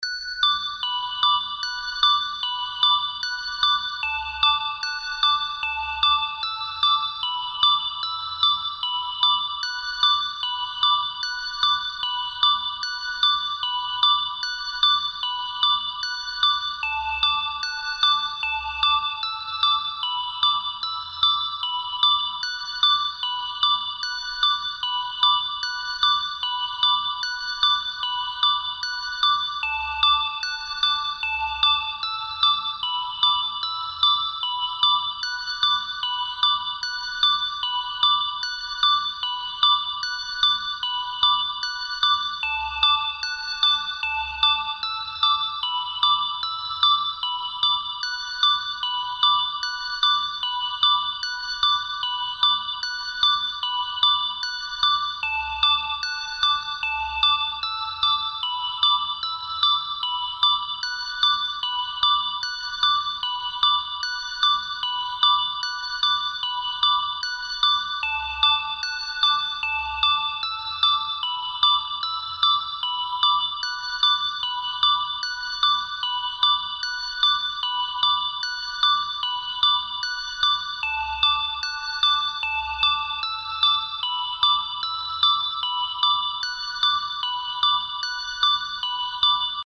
🔹 50 Custom Serum Presets crafted for wave pop, ambient electronica, and deep emotional beats.
These presets feel like water: fluid, lush, and endlessly immersive.
Organic Plucks & Bells – Clean yet soulful, ideal for toplines and arps